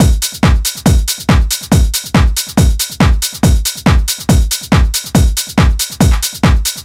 NRG 4 On The Floor 013.wav